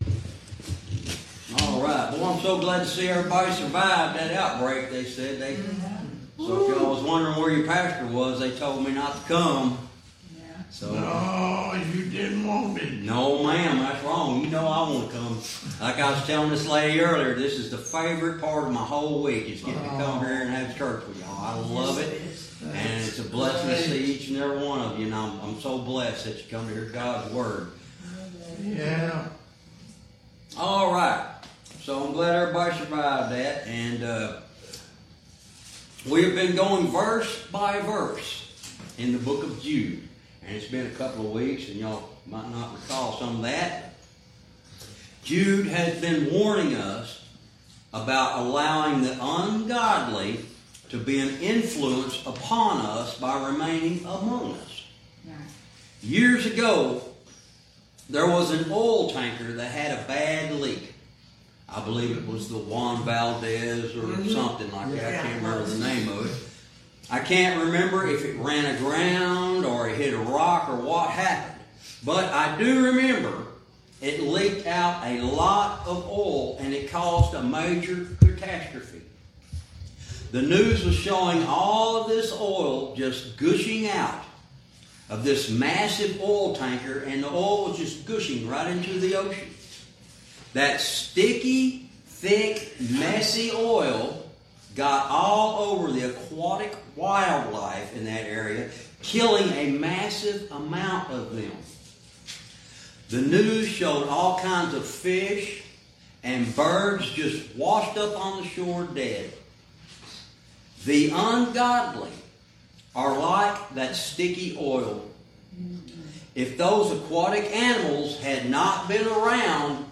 Verse by verse teaching - Lesson 49 verse 12